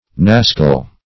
Search Result for " nascal" : The Collaborative International Dictionary of English v.0.48: Nascal \Nas"cal\ (n[a^]s"kal), n. [F. nascale.]
nascal.mp3